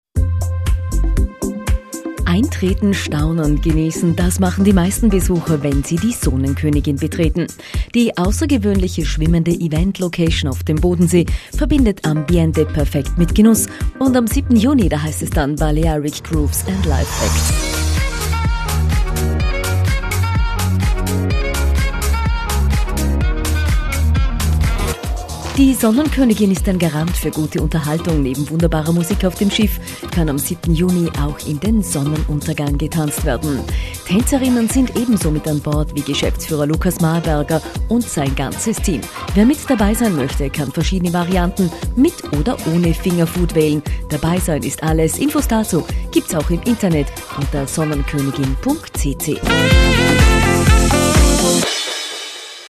Radio-Spot: